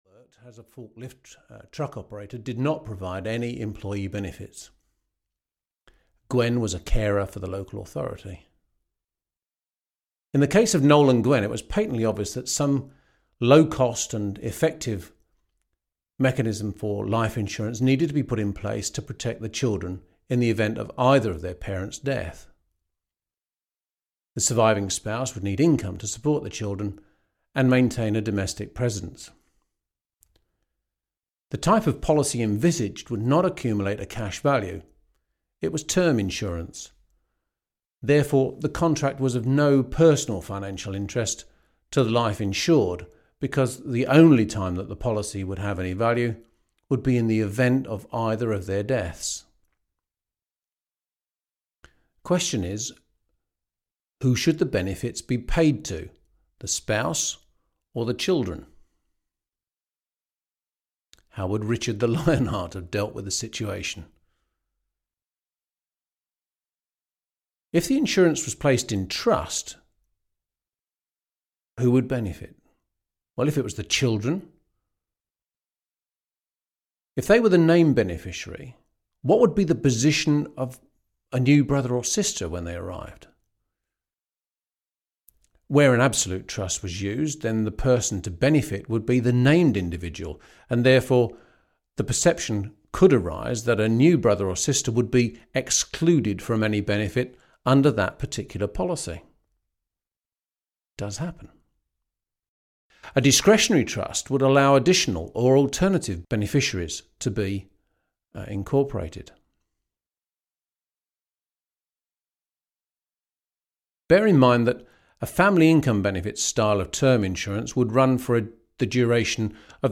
Audio knihaTrusts – A Practical Guide 2 (EN)
Ukázka z knihy